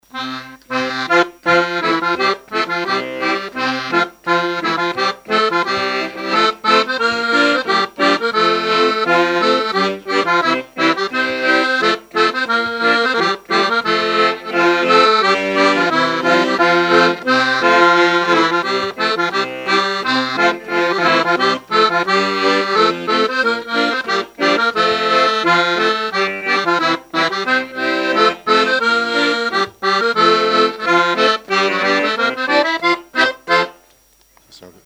Saint-Christophe-du-Ligneron
Chants brefs - A danser
danse : scottich sept pas
Pièce musicale inédite